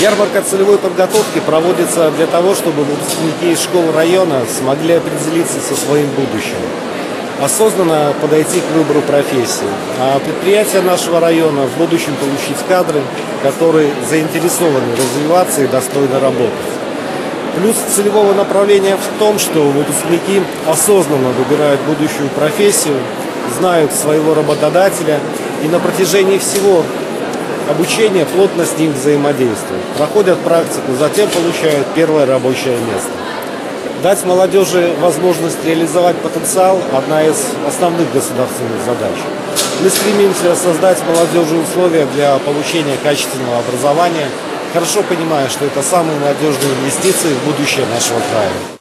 Ярмарка целевой подготовки для учащихся 9-11 классов Барановичского района прошла в Ледовом дворце.
Перед присутствующими выступили председатель районного исполнительного комитета Сергей Карпенко, начальник управления по труду, занятости и социальной защиты Анна Русак, руководитель отдела образования райисполкома Светлана Цимбаленко. Одна из основных задача государства – дать молодёжи возможность реализовать свой потенциал, отметил глава района Сергей Карпенко.